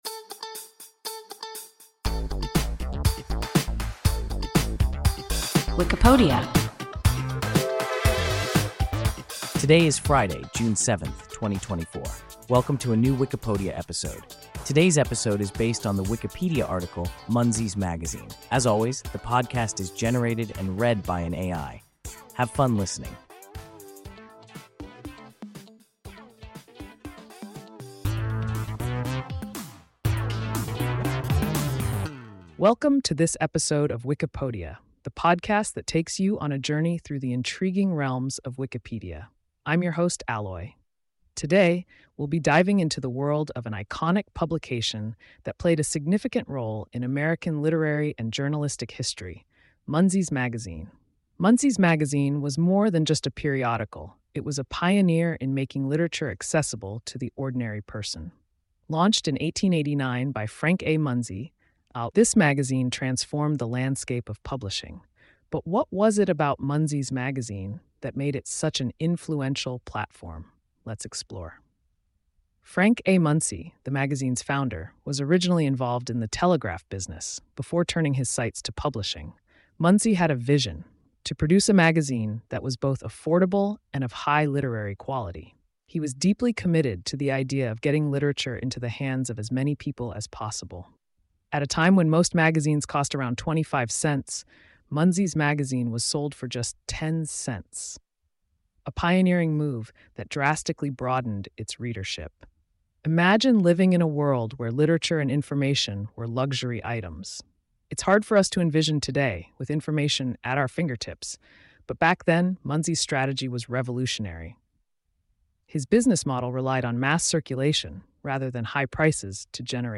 Munsey’s Magazine – WIKIPODIA – ein KI Podcast
Wikipodia – an AI podcast